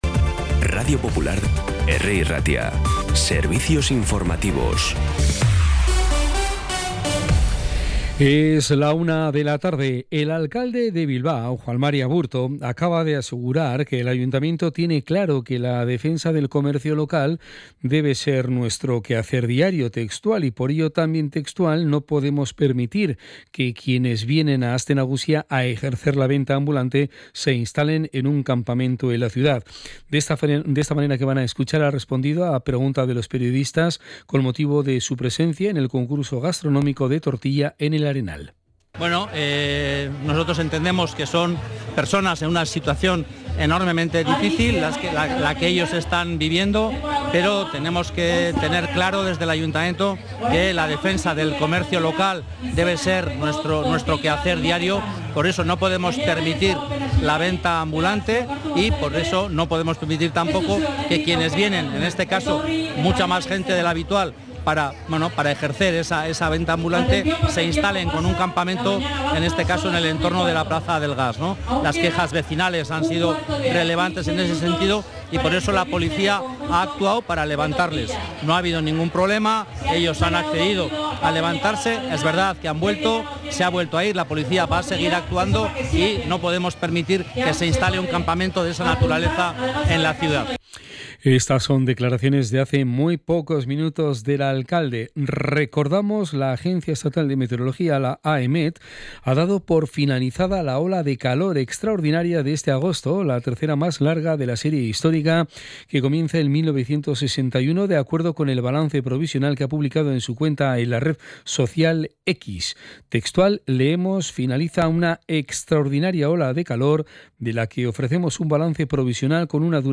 Las noticias de Bilbao y Bizkaia del 19 de agosto a la 13
Los titulares actualizados con las voces del día. Bilbao, Bizkaia, comarcas, política, sociedad, cultura, sucesos, información de servicio público.